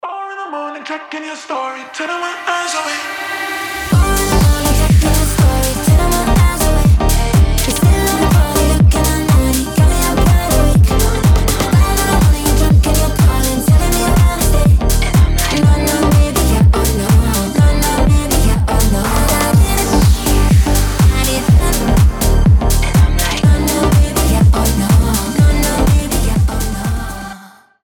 ритмичные
заводные
женский голос
EDM
house